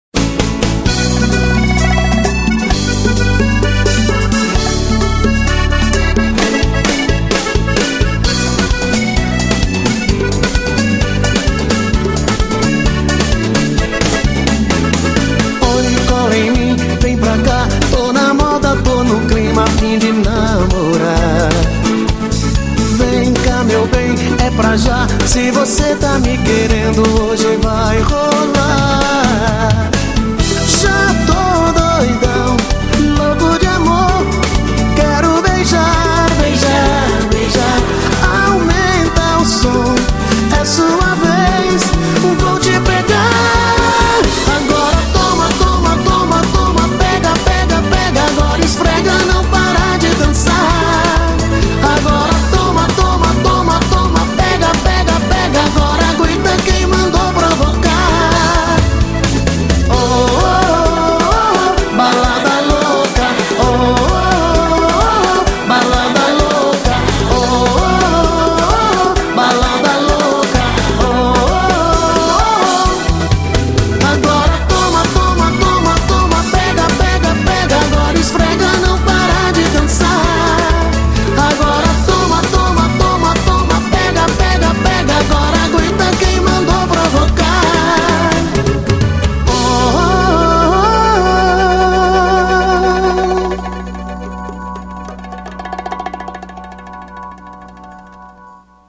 BPM130
Audio QualityLine Out